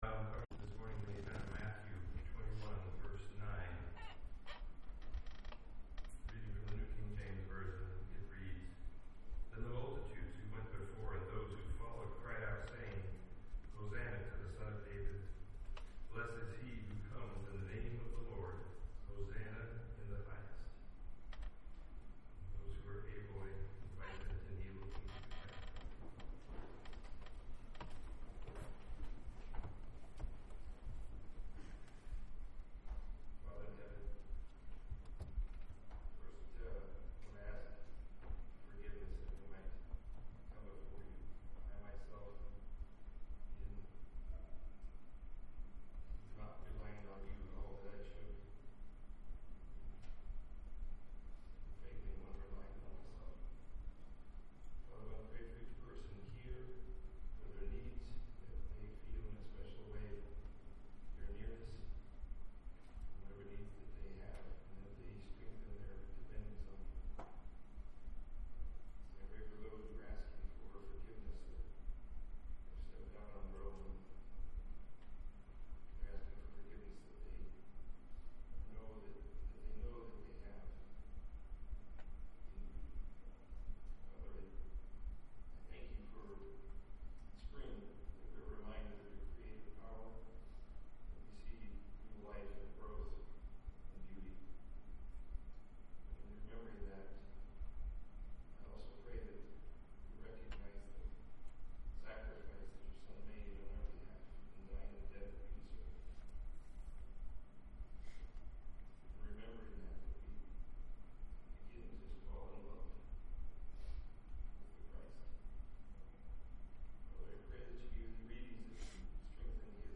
The Spoken Word